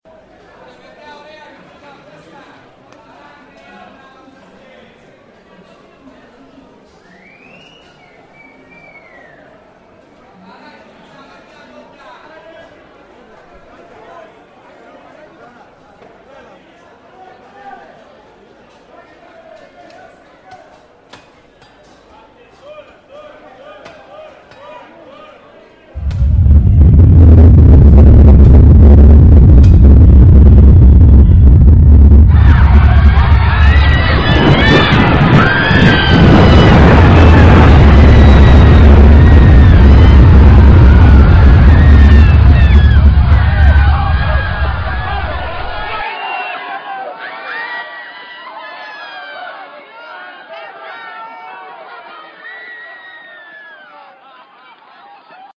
Pompeii Soundscape